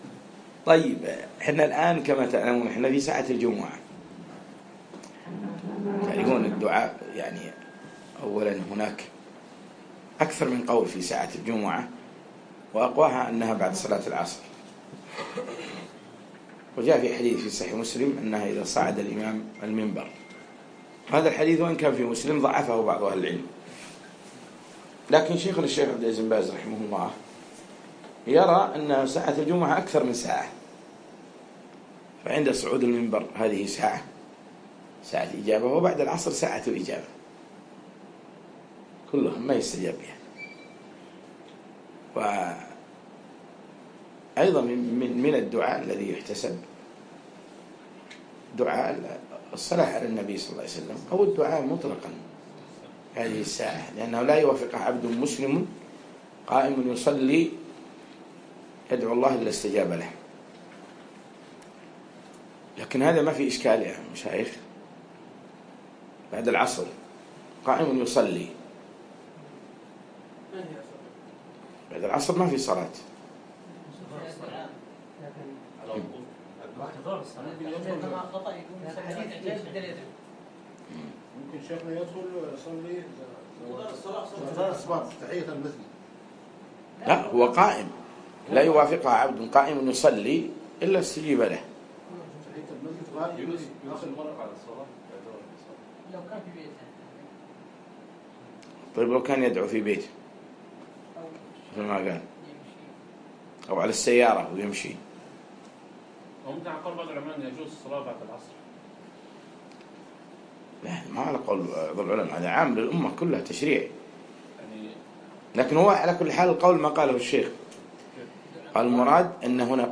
يوم الجمعة 27 شعبان 1437هـ الموافق 3 6 2016م في مكتبة ابن حجر قطر
الدرس الثالث